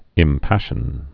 (ĭm-păshən)